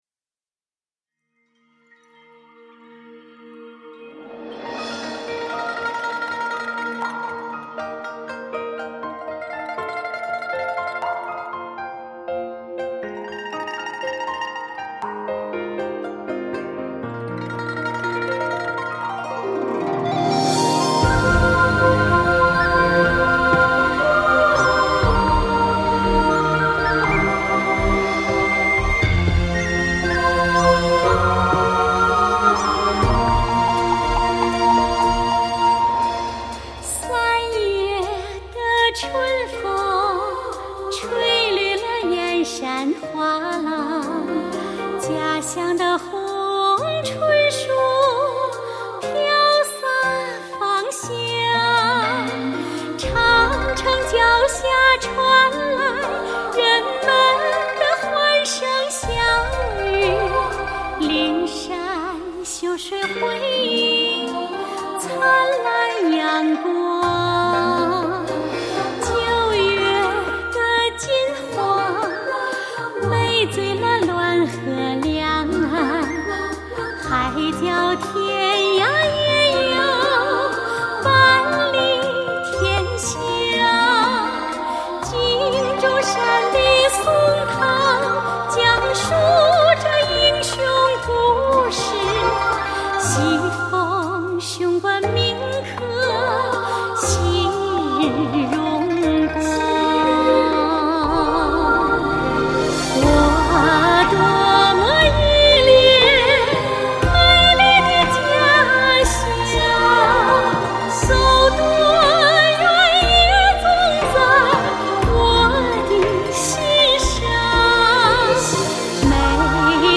清新优美的旋律和悠扬婉转的演唱